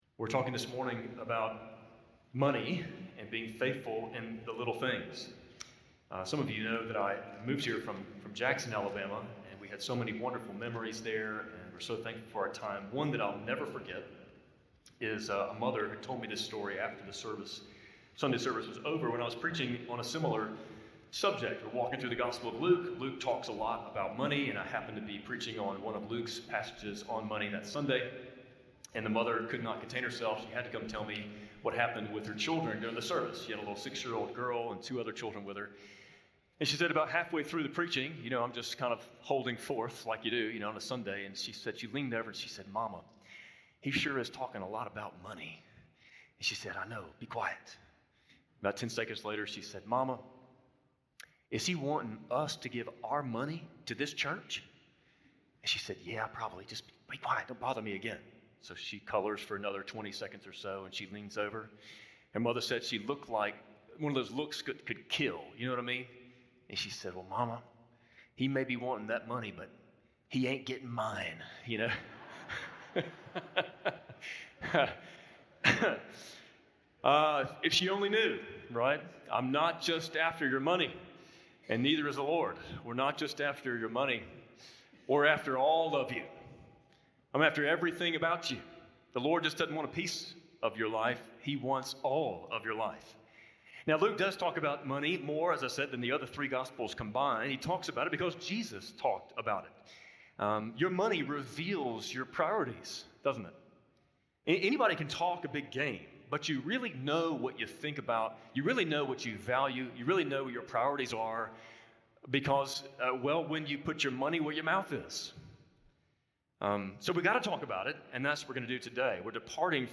An episode by Spring Hill Baptist Sunday Sermons (Audio)